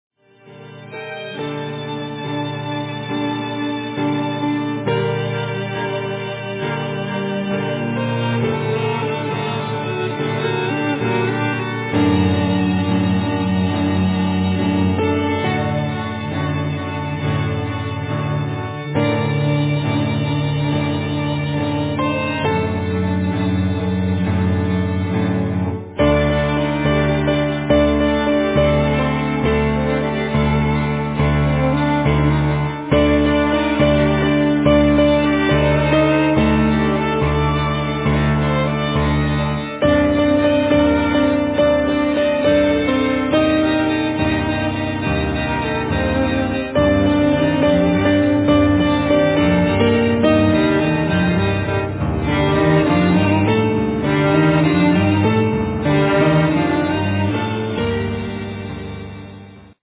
仏のハイセンスな室内楽団デビュー作
violin, viola, vocals
Cello
piano, percussions
french horn